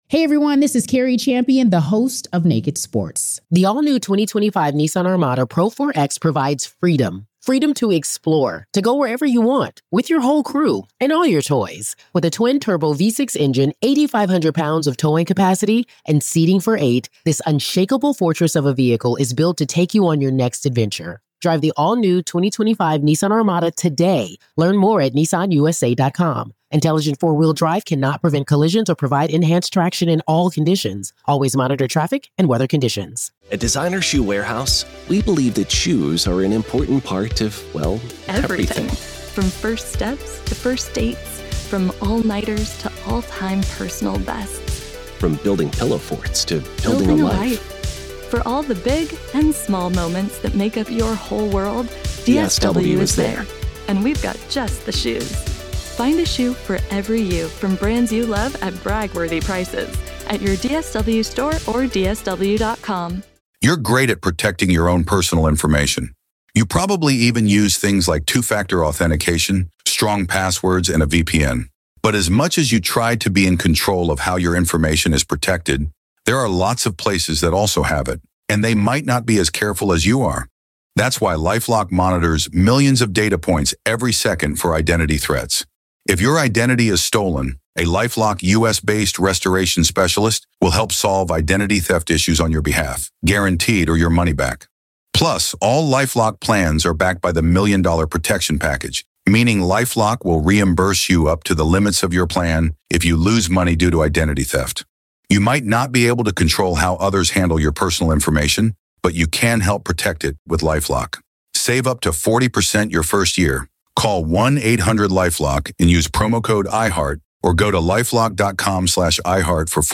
True Crime Today | Daily True Crime News & Interviews / Does The Current FBI Chief Even Have The REAL Epstein Evidence?